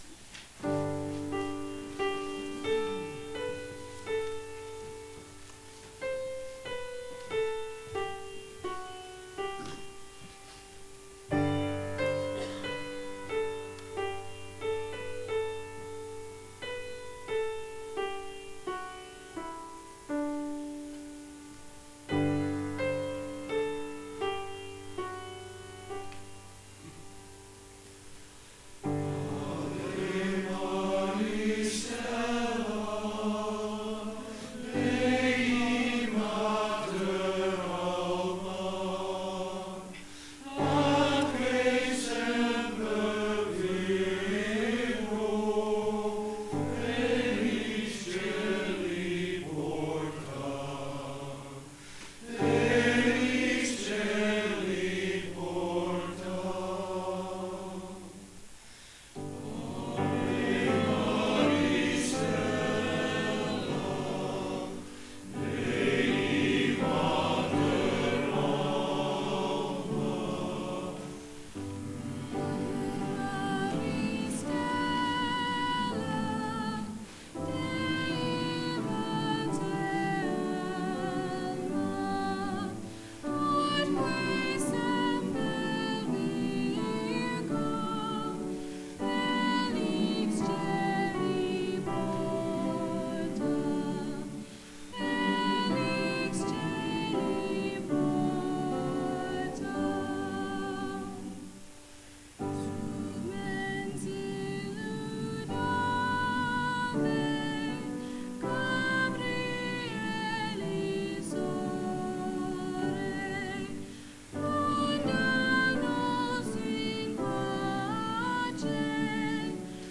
Guelph Male Choir - Christmas Concert - 2017